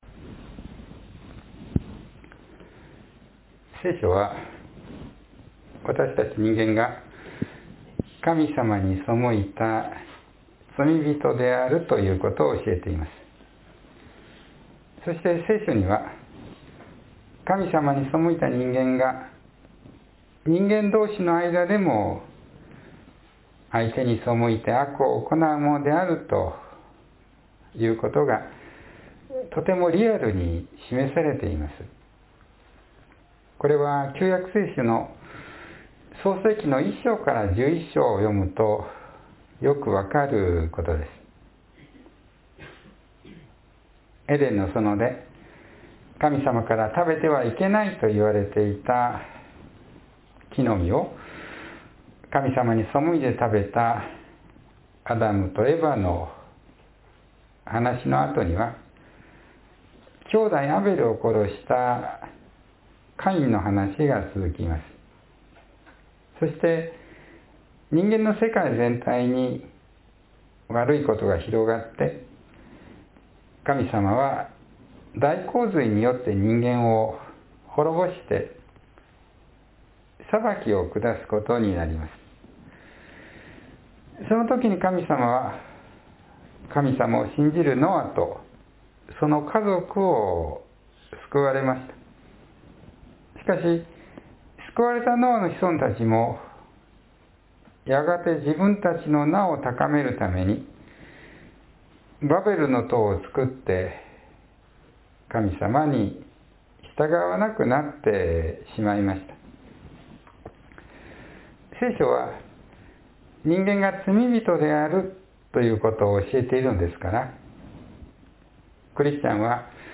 （3月27日の説教より）